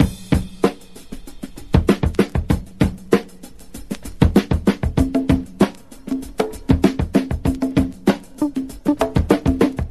• 97 Bpm High Quality Drum Groove E Key.wav
Free drum loop sample - kick tuned to the E note. Loudest frequency: 658Hz
97-bpm-high-quality-drum-groove-e-key-hd2.wav